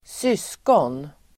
Ladda ner uttalet
syskon substantiv, siblings , brothers and sisters Uttal: [²s'ys:kån]